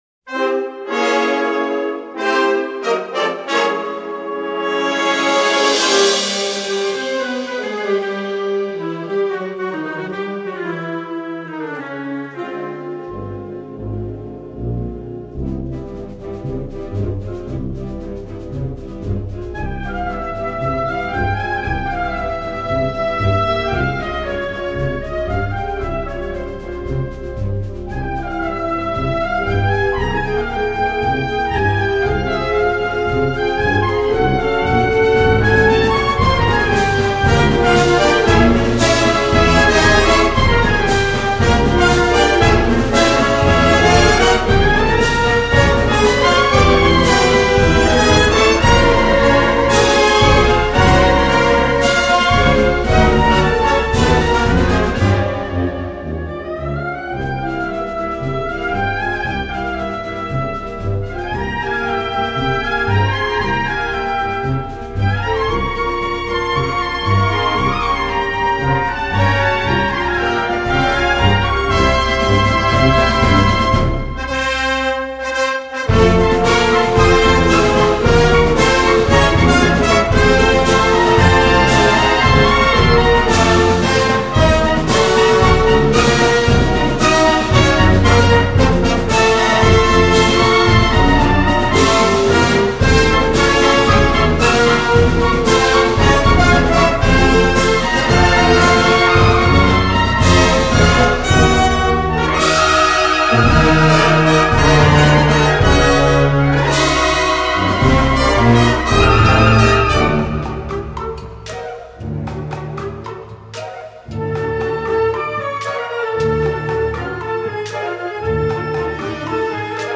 Género: Marchas Moras